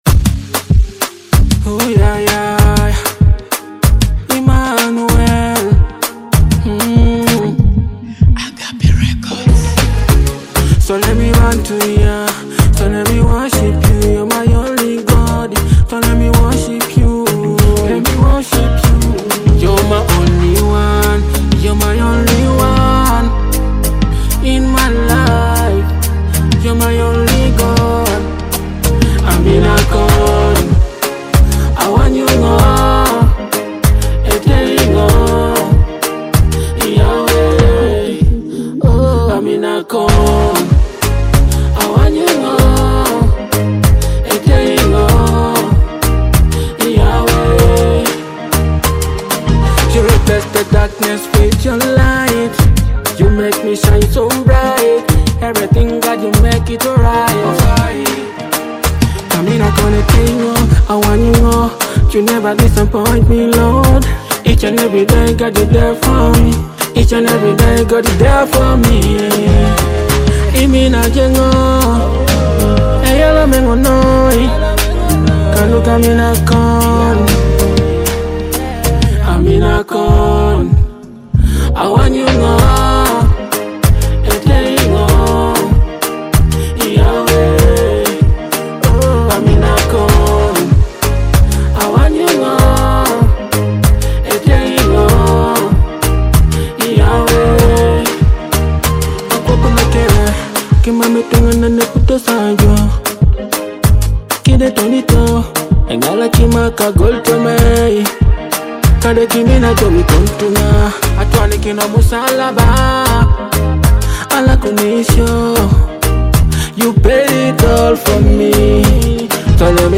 spirit-filled gospel track
With its rich vocals and moving melodies